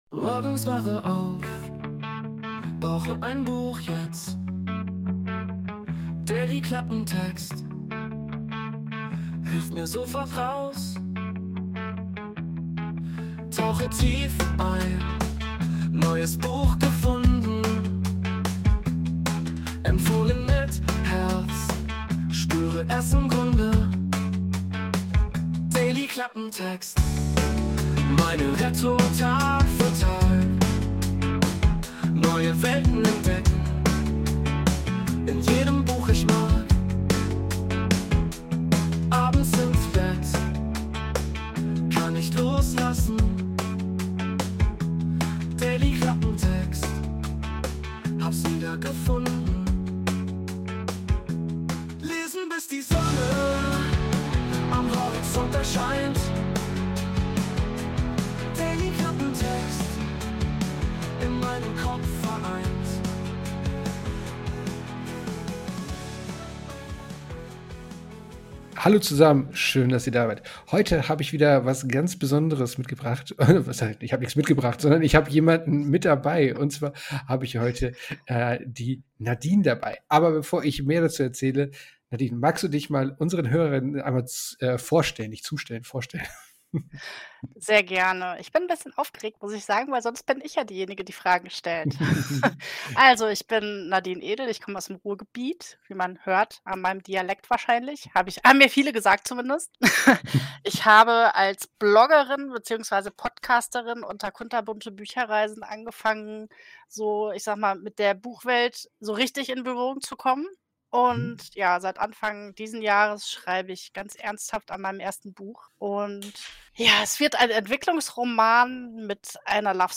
Und zwar: ein Interview.
Intromusik: Wurde mit der KI Suno erstellt.